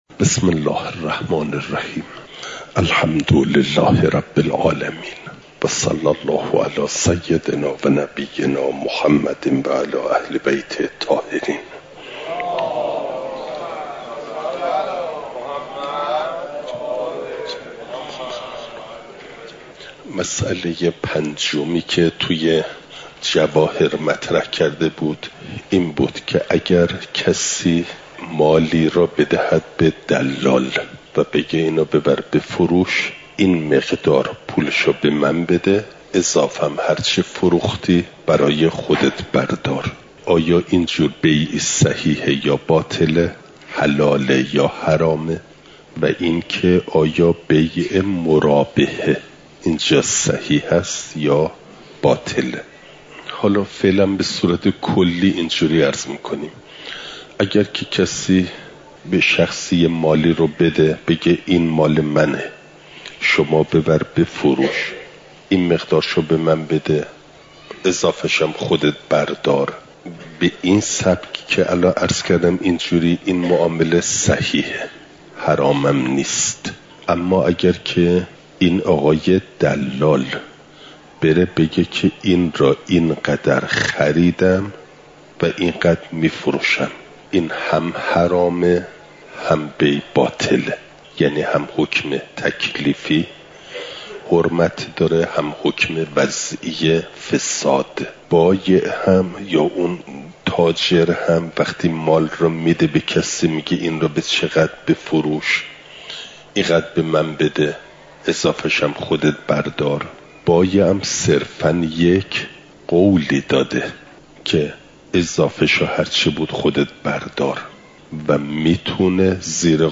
مسائل مستحدثه قضا (جلسه۴۸) – دروس استاد